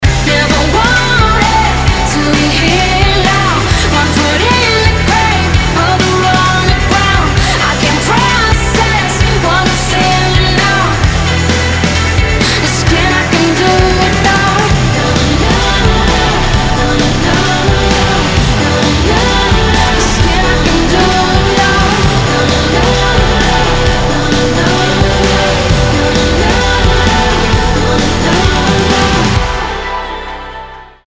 • Качество: 320, Stereo
красивые
женский вокал
indie rock
легкий рок